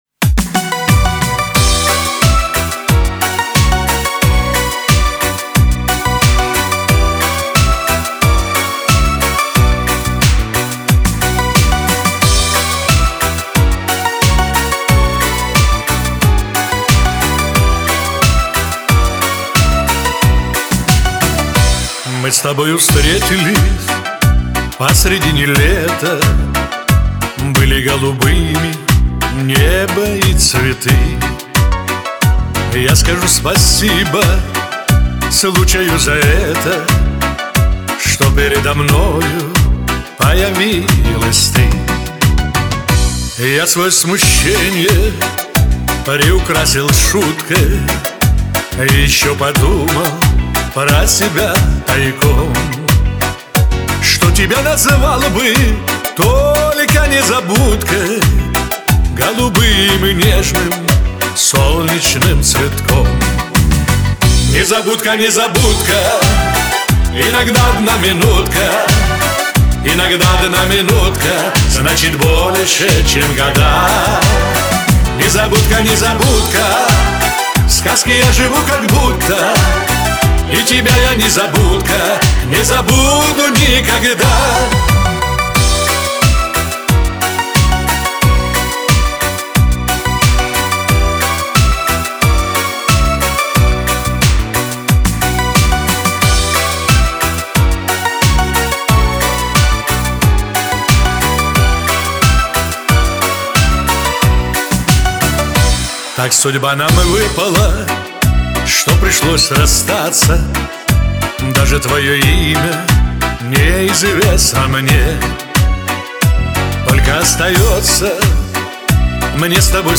боле нежно и протяжно